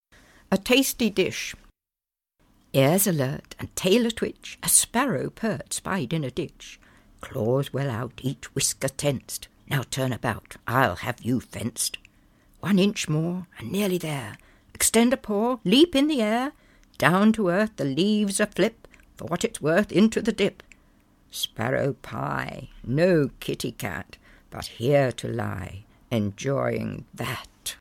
Audio knihaHyam the Cat Who Talked Too Much (EN)
Ukázka z knihy